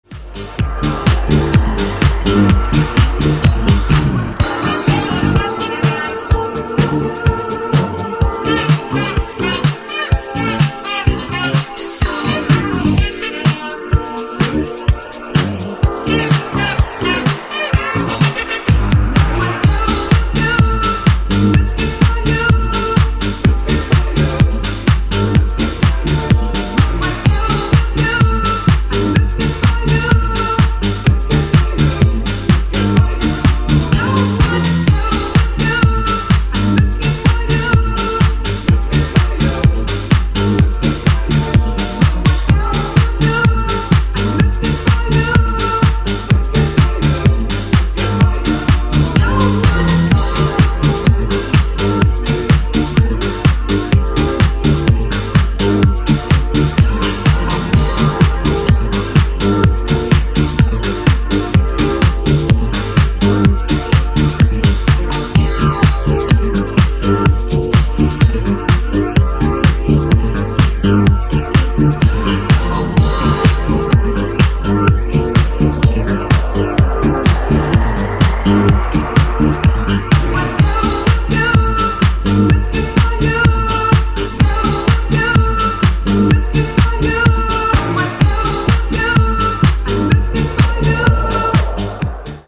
ジャンル(スタイル) DISCO HOUSE / DEEP HOUSE